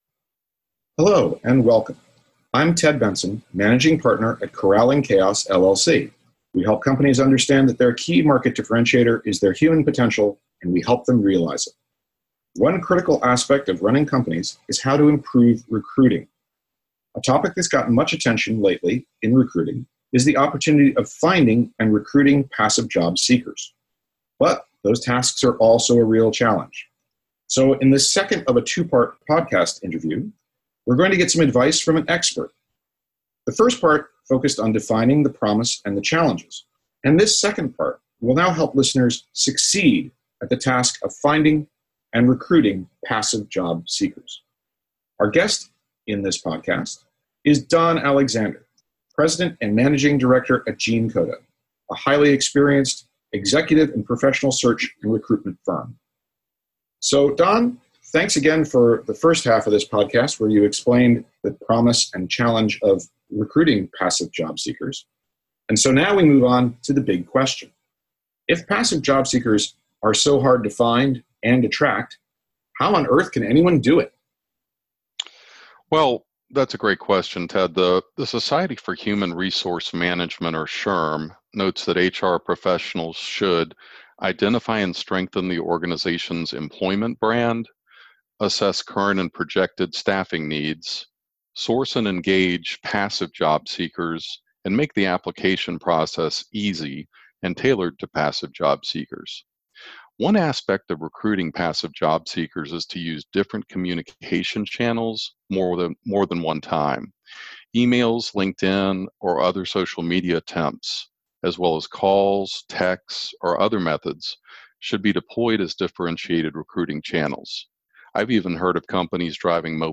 There are two episodes to this interview.